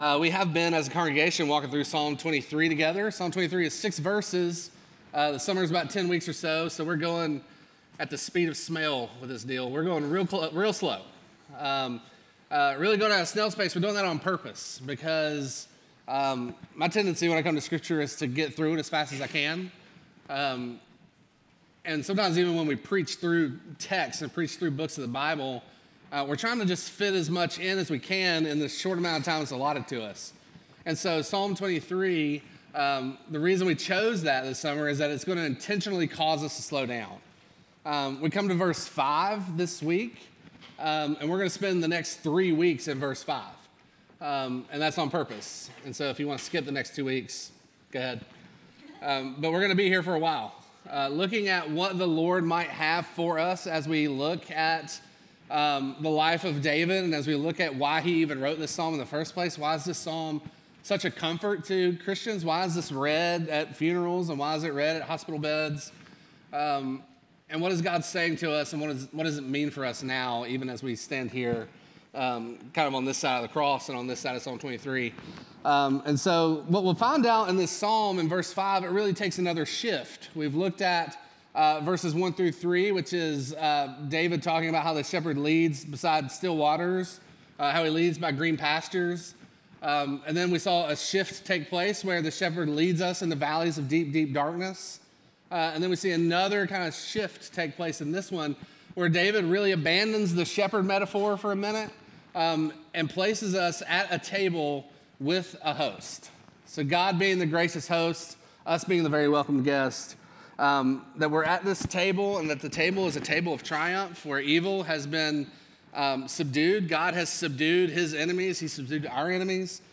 Midtown Fellowship 12 South Sermons The Table of Triumph Jul 14 2024 | 00:33:44 1x 00:00 / 00:33:44 Subscribe Share Apple Podcasts Spotify Overcast RSS Feed Share Link Embed